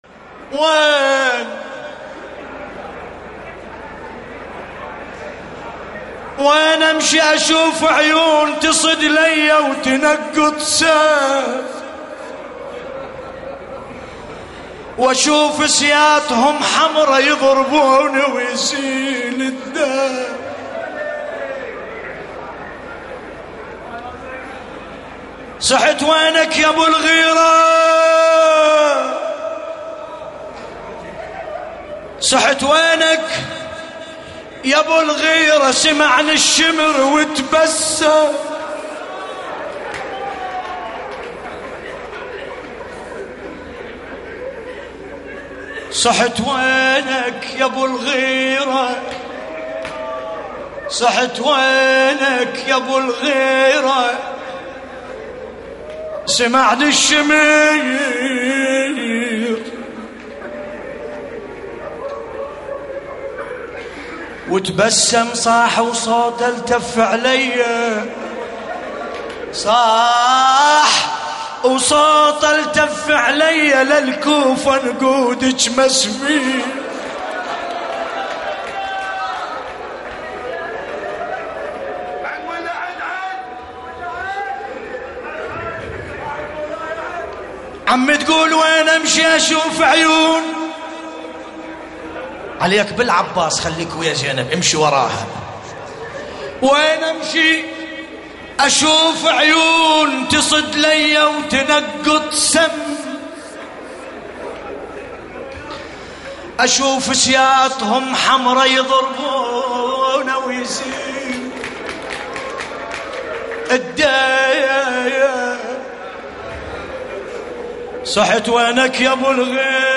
الرادود : الحاج ملا باسم الكربلائی
المناسبة : ليلة 7 محرم 1435 هـ حسينية داود العاشور - البصرة